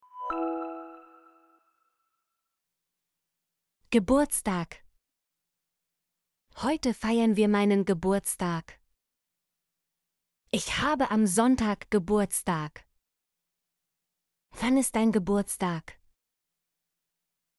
geburtstag - Example Sentences & Pronunciation, German Frequency List